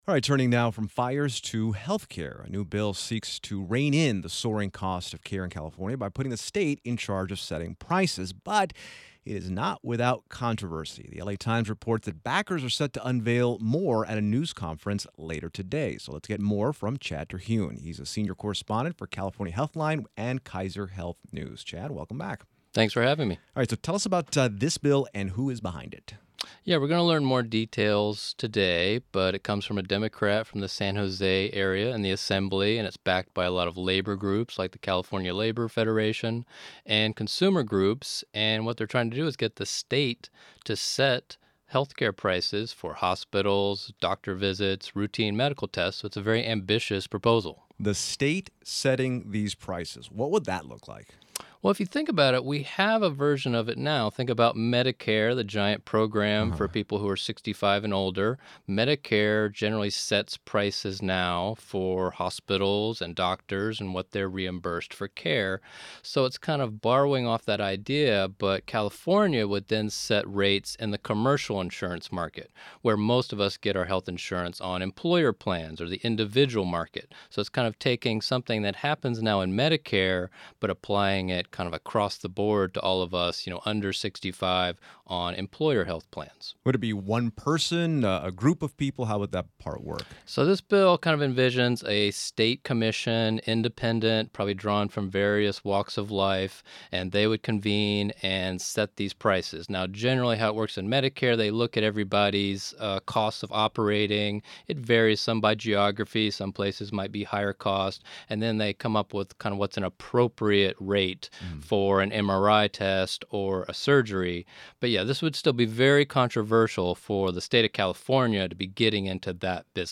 Audio Report Cost and Quality Health Care Costs Health Industry Multimedia